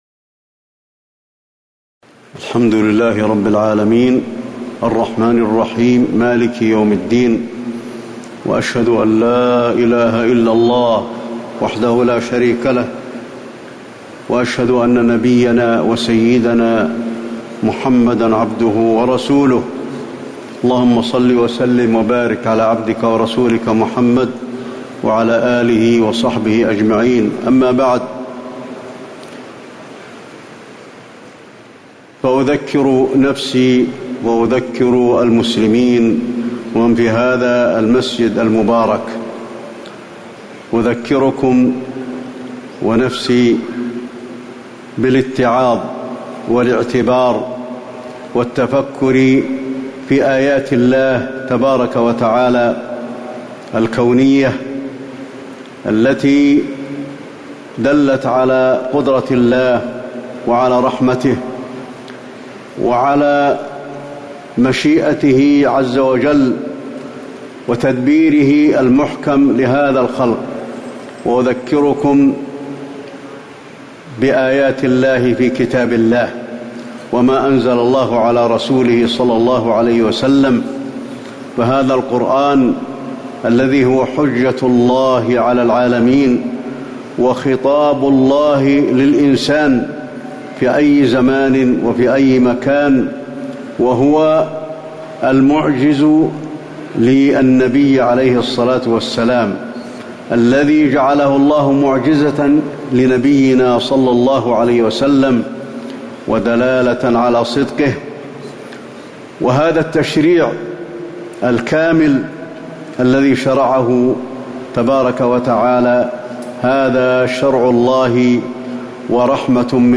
خطبة الخسوف المدينة- الشيخ علي الحذيفي
تاريخ النشر ١٤ ذو القعدة ١٤٣٩ هـ المكان: المسجد النبوي الشيخ: فضيلة الشيخ د. علي بن عبدالرحمن الحذيفي فضيلة الشيخ د. علي بن عبدالرحمن الحذيفي خطبة الخسوف المدينة- الشيخ علي الحذيفي